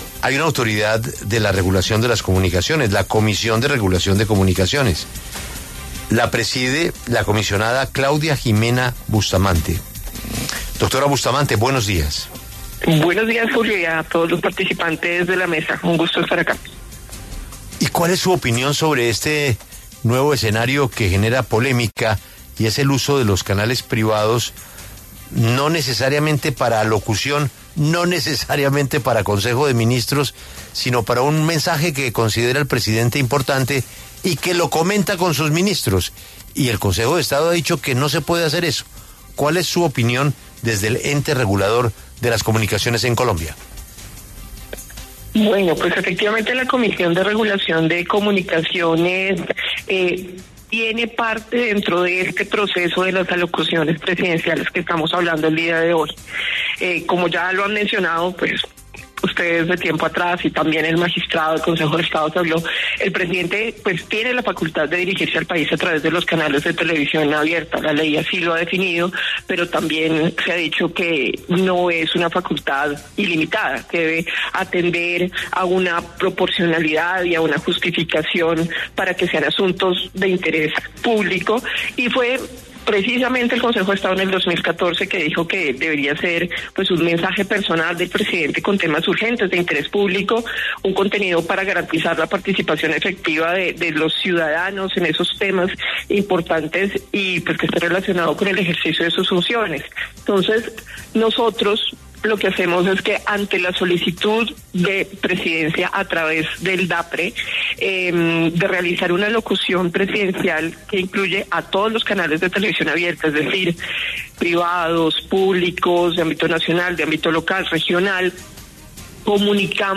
Claudia Ximena Bustamante, presidenta de la Comisión de Regulación de Comunicaciones, explicó en La W que las alocuciones deben ser justificadas y no pueden usarse para transmitir consejos de ministros.